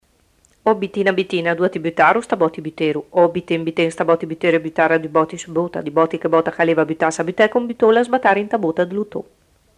scioglilingua